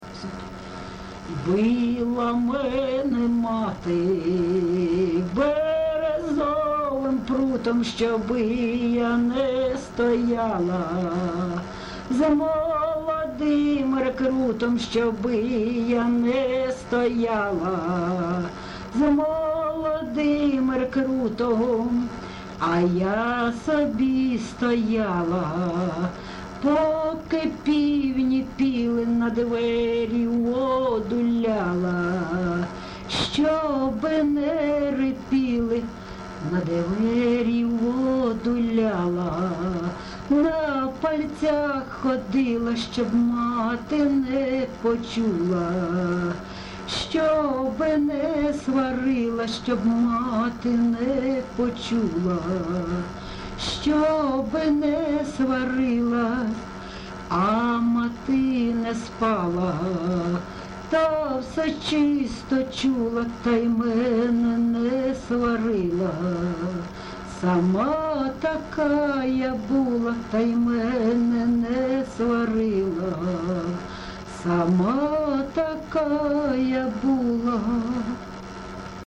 ЖанрПісні з особистого та родинного життя, Пісні літературного походження, Сучасні пісні та новотвори
Місце записус. Лозовівка, Старобільський район, Луганська обл., Україна, Слобожанщина